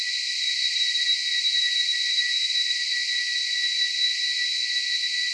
rr3-assets/files/.depot/audio/sfx/forced_induction/f1_spool.wav